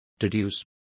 Also find out how sobreentender is pronounced correctly.